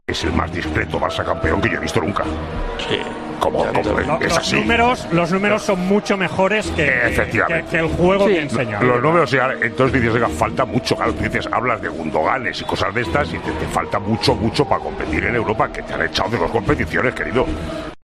El comentarista de 'Tiempo de Juego' y de 'El Partidazo de COPE' se mostró crítico tras la consecución del título liguero del conjunto azulgrana.
En Tiempo de Juego, durante la retransmisión del partido Espanyol-Barcelona, se habló de LaLiga conquistada por el Barcelona.